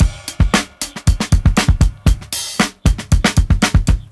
• 116 Bpm Drum Loop G Key.wav
Free drum loop sample - kick tuned to the G note. Loudest frequency: 1703Hz
116-bpm-drum-loop-g-key-70D.wav